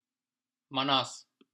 How to pronounce Mannaz